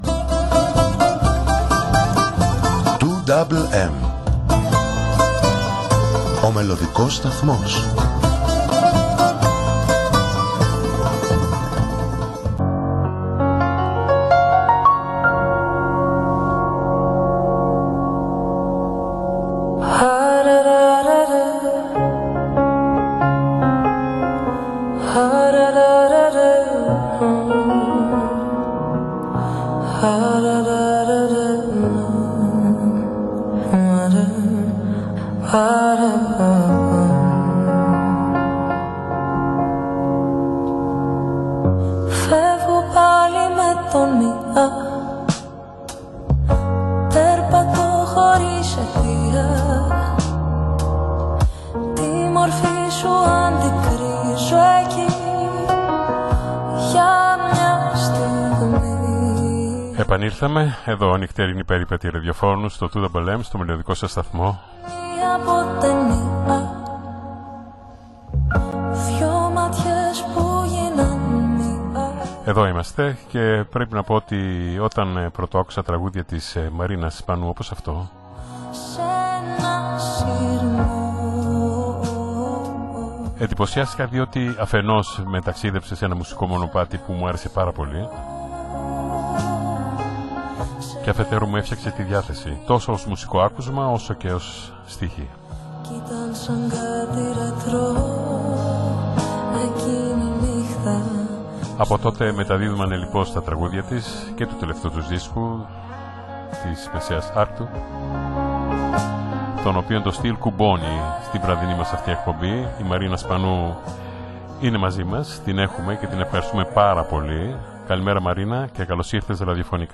στο ελληνόφωνο ραδιοσταθμό